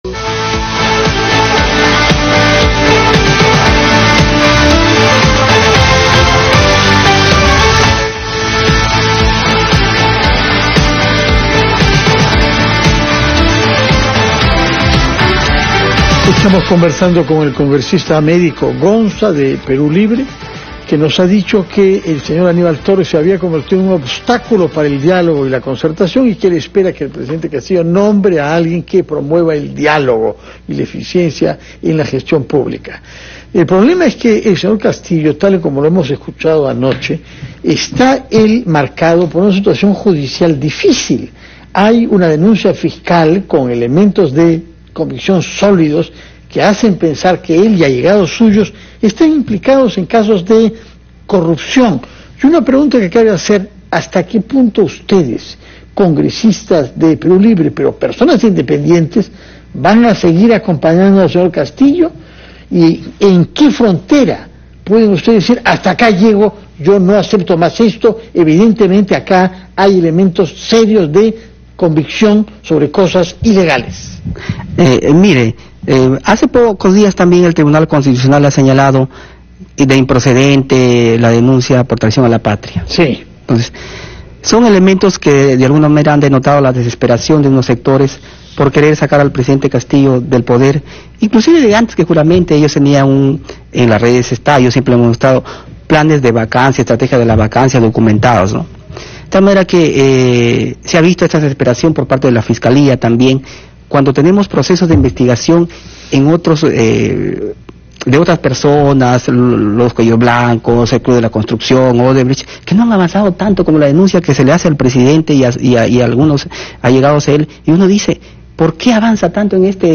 Entrevista al congresista de Perú Libre, Américo Gonza (parte 2)